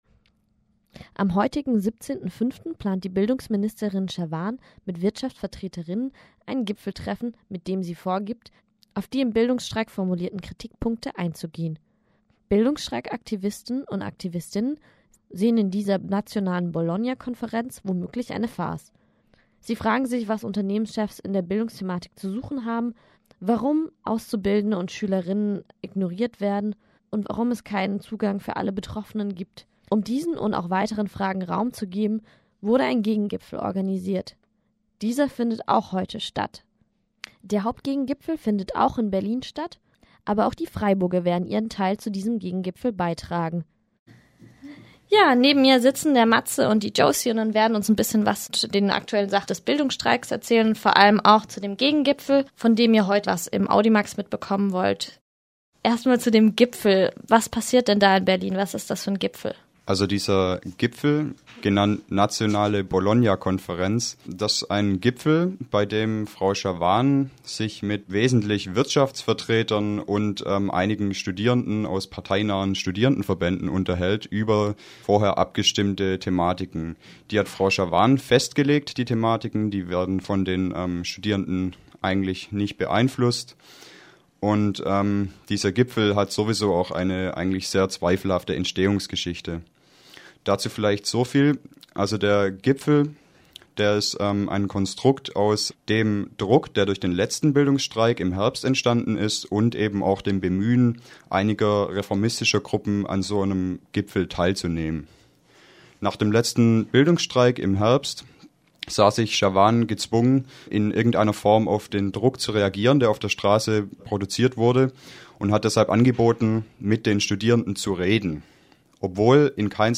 Interview mit zwei BildungsstreikaktivistInnen zum Gegengipfel und dem Gipfel von Bildungsministerin